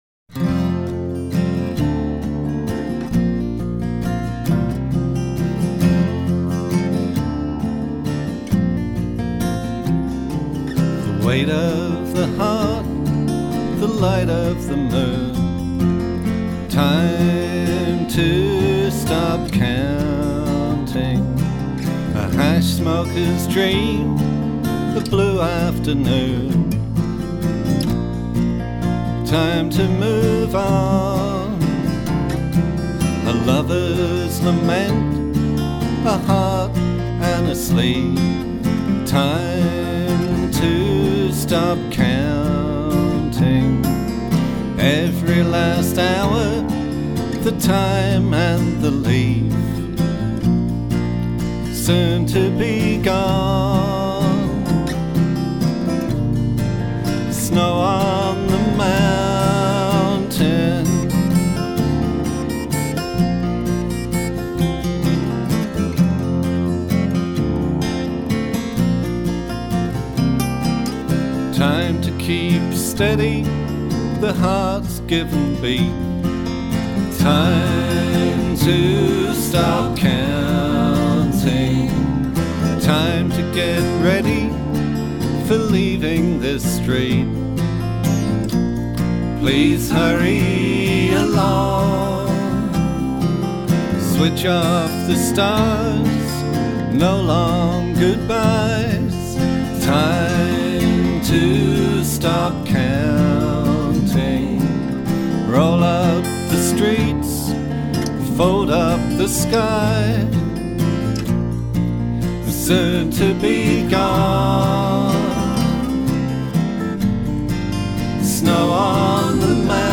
vocals, acoustic guitar
acoustic guitar, banjo
electric guitar, vocals
bass
saxes, whistles, vocals
dobro
in West Bridgford, Nottingham